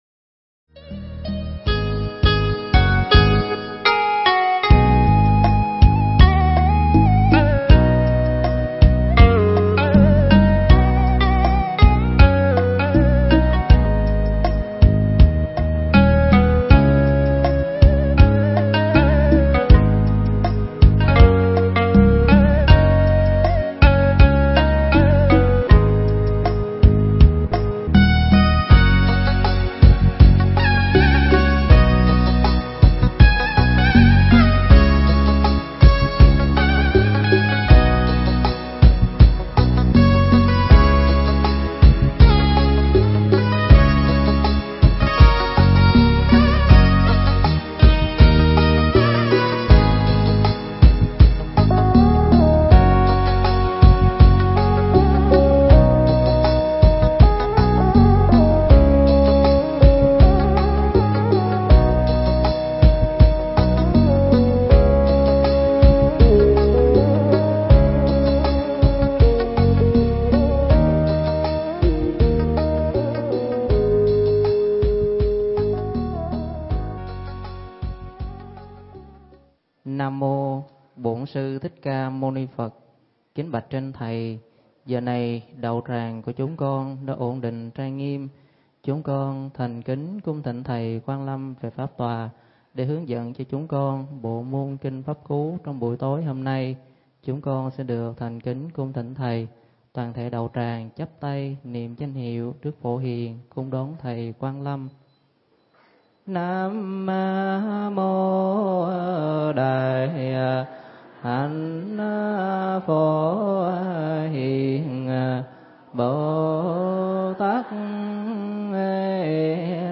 Mp3 Pháp Thoại Kinh Pháp Cú Phẩm Ái Dục
giảng tại Tu Viện Tường Vân (Huyện Bình Chánh, HCM)